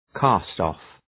Προφορά
{‘kæstɒf}